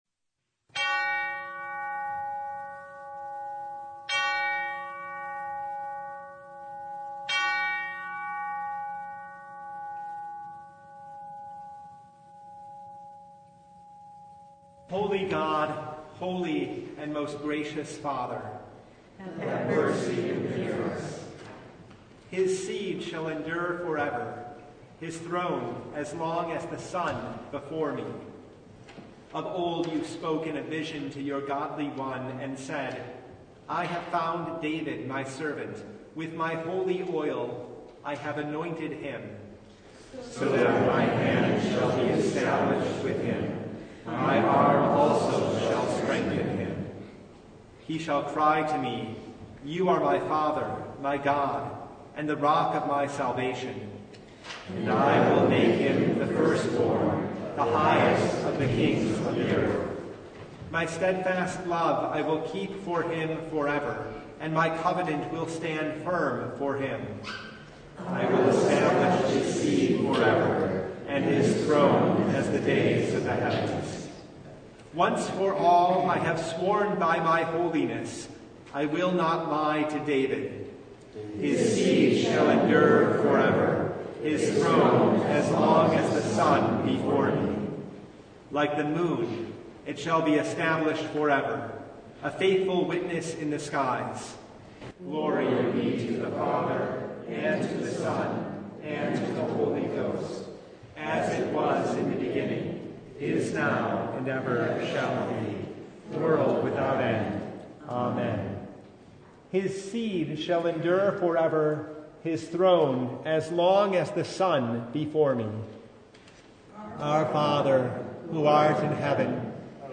Passage: 2 Samuel 7:1-18 Service Type: Advent Noon
Full Service